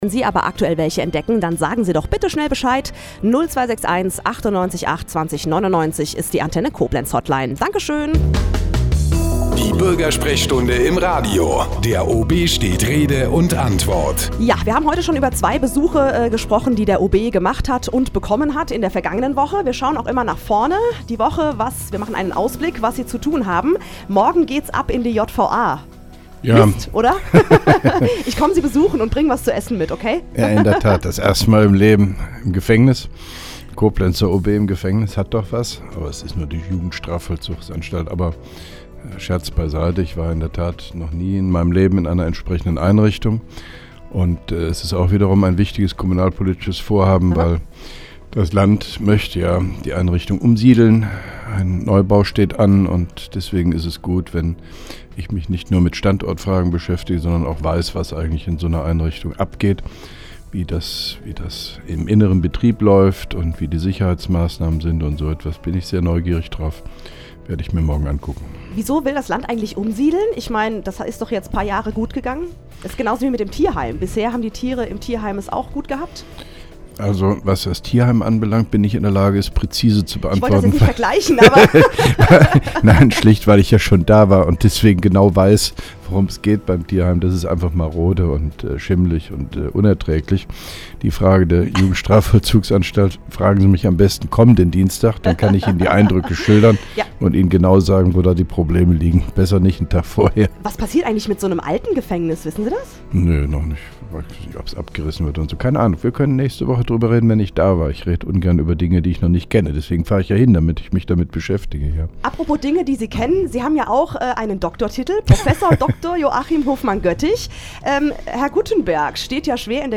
(2) Koblenzer Radio-Bürgersprechstunde mit OB Hofmann-Göttig 22.02.2011
Interviews/Gespräche